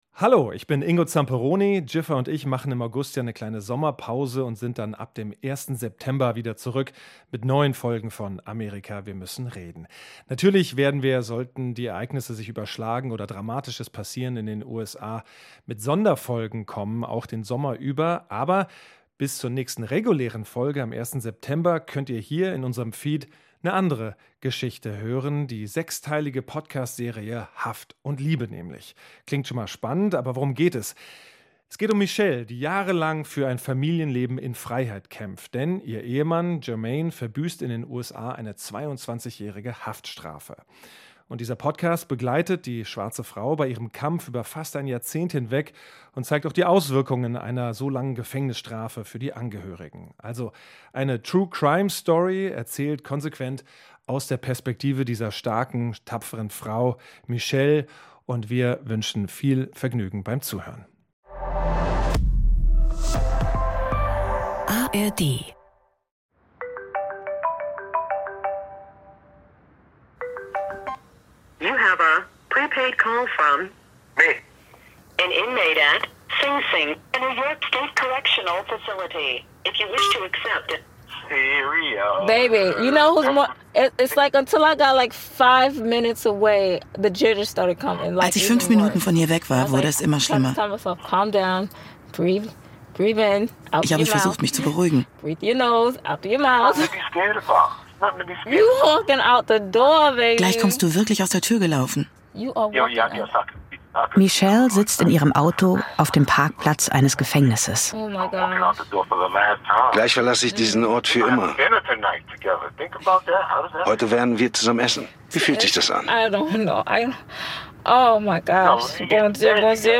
eine True Crime-Story aus konsequent weiblicher Perspektive.